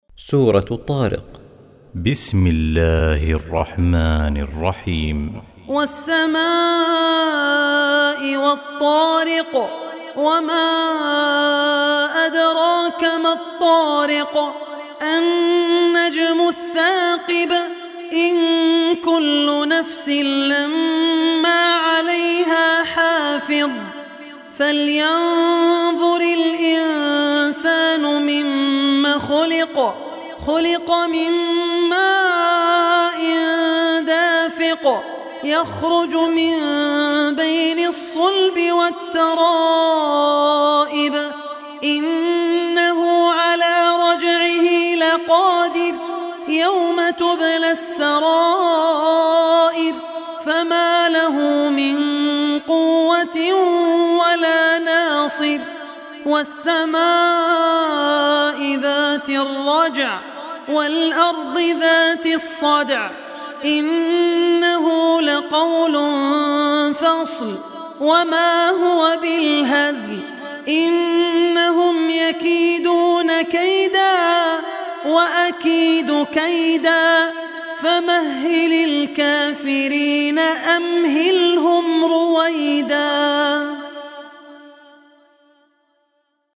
Surah At-Tariq Recitation by Abdur Rehman Al Ossi